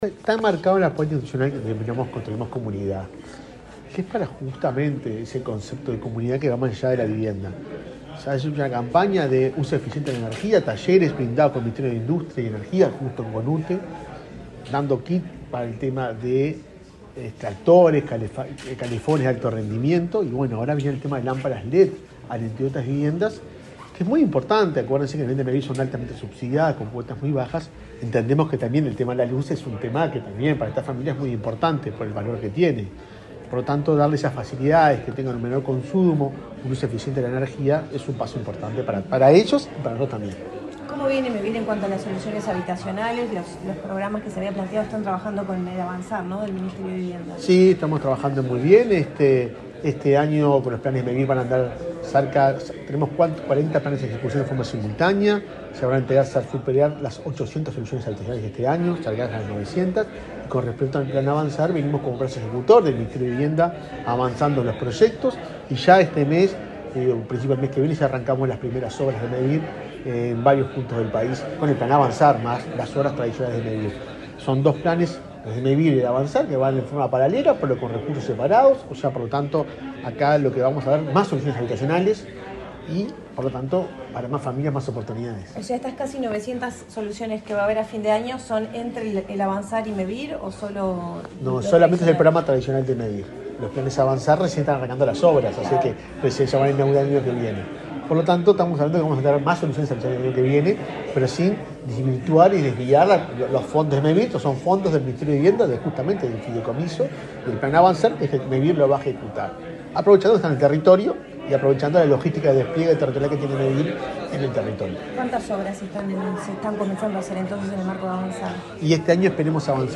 Declaraciones del presidente de Mevir, Juan Pablo Delgado
Declaraciones del presidente de Mevir, Juan Pablo Delgado 31/10/2022 Compartir Facebook X Copiar enlace WhatsApp LinkedIn Este lunes 31 en Montevideo, el titular de Mevir, Juan Pablo Delgado, firmó un convenio con la presidenta de UTE, Silvia Emaldi, y luego dialogó con la prensa.